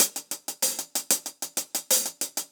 Index of /musicradar/ultimate-hihat-samples/95bpm
UHH_AcoustiHatB_95-03.wav